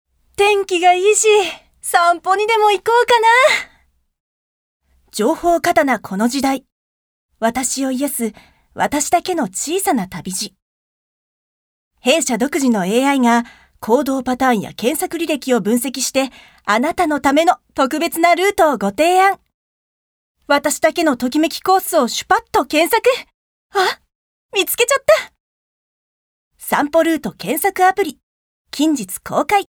ボイスサンプル、その他
ナレーション２